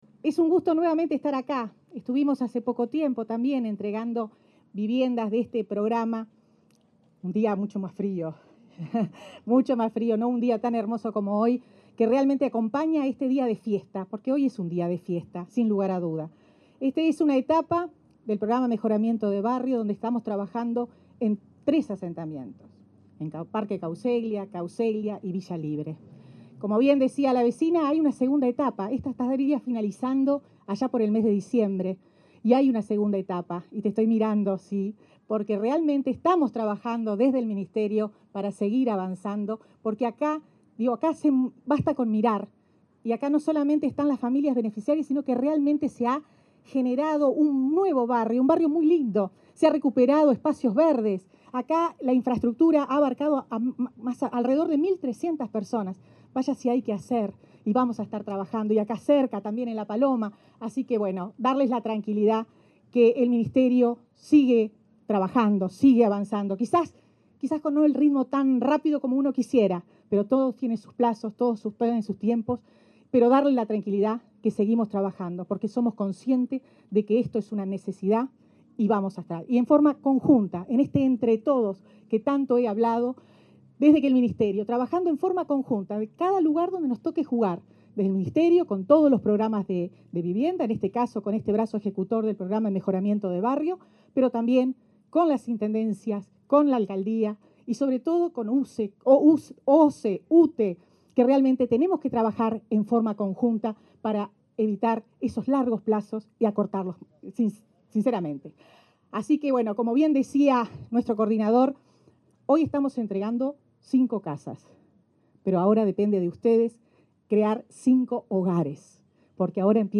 Palabras de la ministra de Vivienda, Irene Moreira
Palabras de la ministra de Vivienda, Irene Moreira 11/11/2021 Compartir Facebook X Copiar enlace WhatsApp LinkedIn En el marco del proyecto de regularización de los asentamientos de Parque Cauceglia, Nuevo Cauceglia y Villa Libre, la ministra de Vivienda, Irene Moreira, entregó este jueves 11, nuevas soluciones habitaciones a familias que fueron reubicadas en la zona.